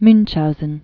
(münhouzən) also Mun·chau·sen (mŭnchou-, mŭnchhou-), Baron Karl Friedrich Hieronymus von 1720-1797.